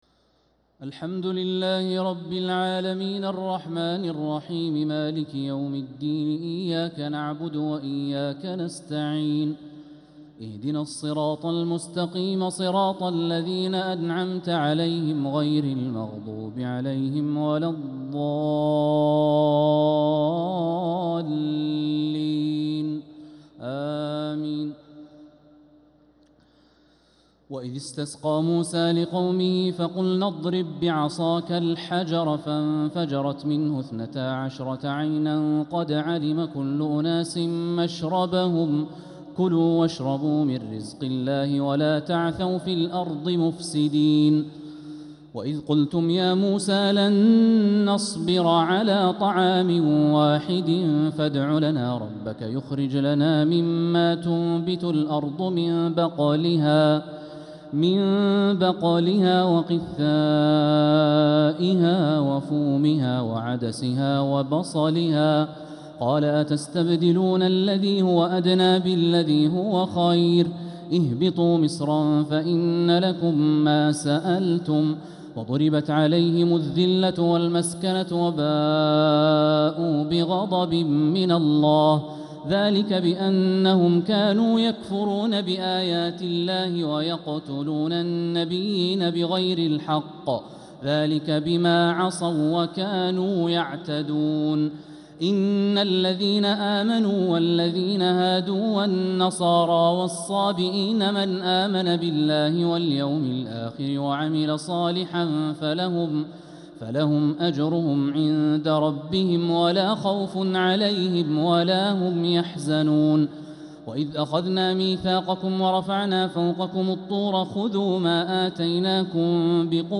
تراويح ليلة 1 رمضان 1446هـ من سورة البقرة (60-91) | Taraweeh 1st night Ramadan 1446H > تراويح الحرم المكي عام 1446 🕋 > التراويح - تلاوات الحرمين